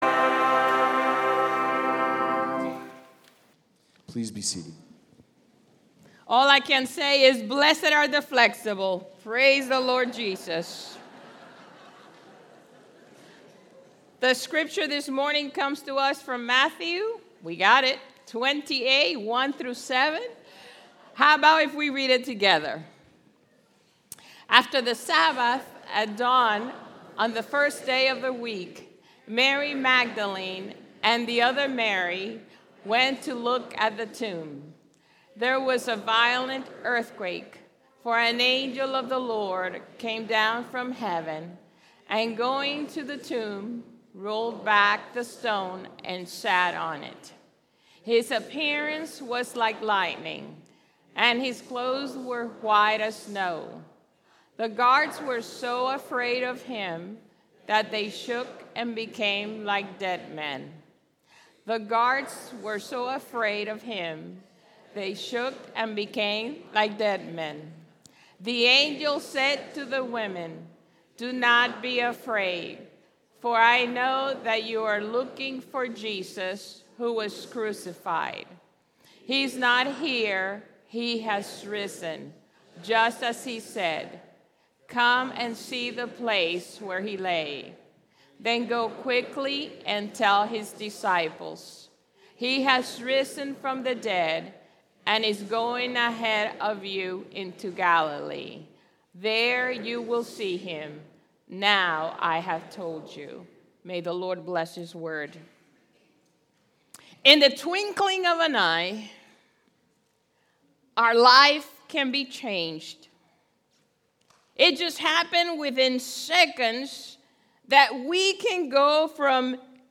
East Naples United Methodist Church Sermons